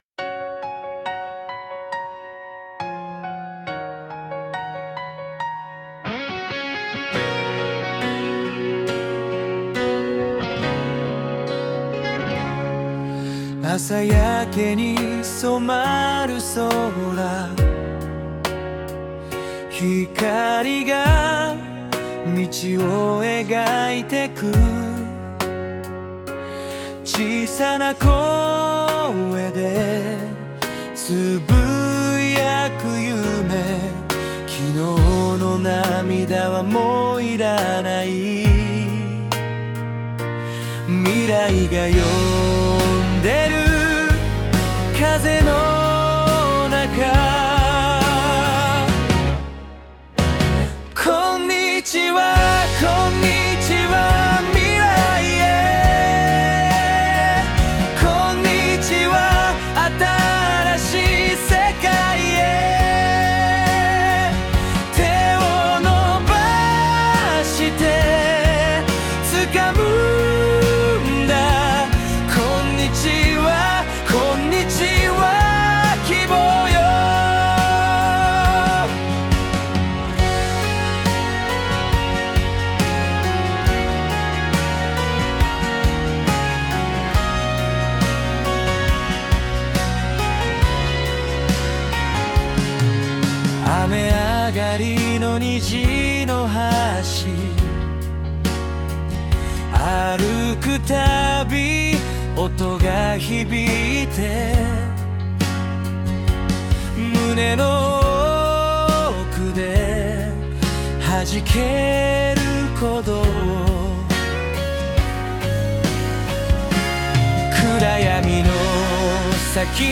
Sunoは音楽専門の生成AIです。生成される楽曲のクオリティが非常に高く、特にボーカルの歌唱が非常に自然であることで注目が集まりました。
男性ボーカル、明るいロック
さすがに意識した楽曲の壮大なスケールには及びませんが、たったこれだけのプロンプトでこれだけの楽曲ができあがるとは、感嘆を通り越して怖さすら感じます。